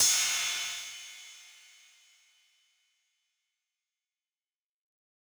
MB Perc (12).wav